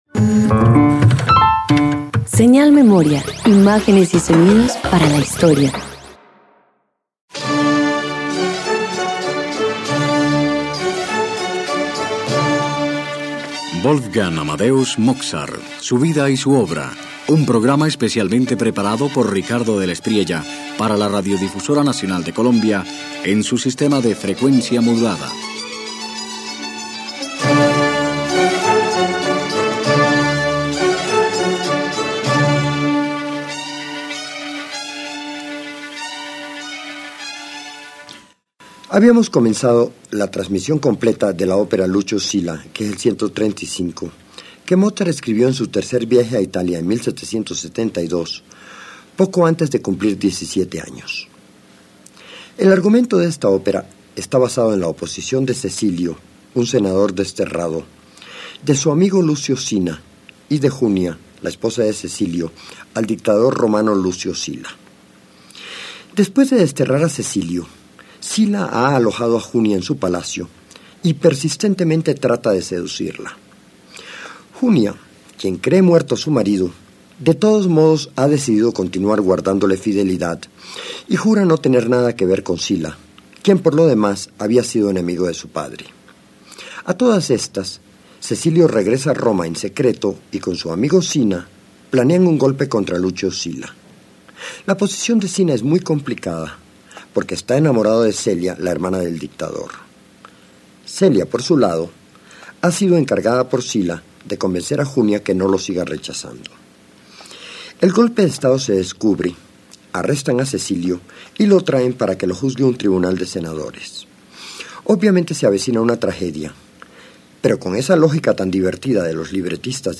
Mozart avanza en el segundo acto de Lucio Silla, donde las tensiones políticas y amorosas se intensifican mientras la música refleja el carácter marcial y la rigidez del régimen que comienza a resquebrajarse en medio de manipulaciones.